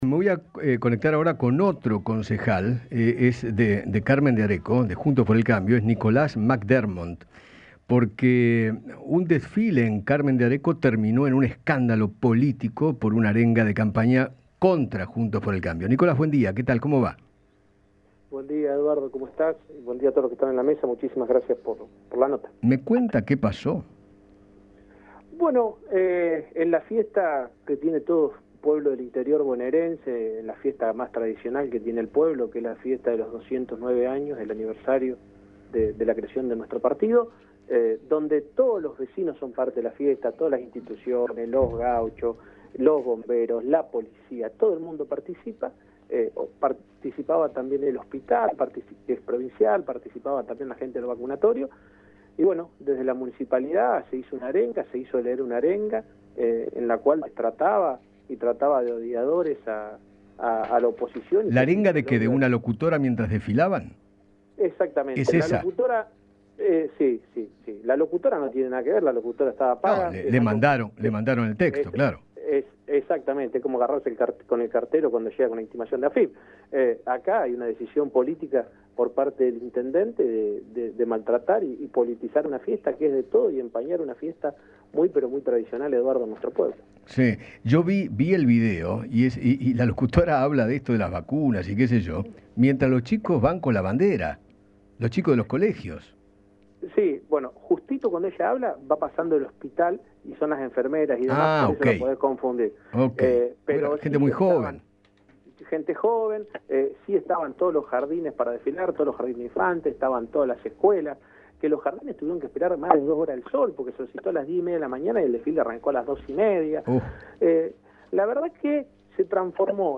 Nicolás Mc Dermott, concejal de Carmen de Areco, conversó con Eduardo Feinmann sobre el escándalo que se desató en la celebración de la fiesta más tradicional de aquel pueblo, luego de que desde la municipalidad arenguen en contra de la oposición.